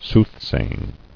[sooth·say·ing]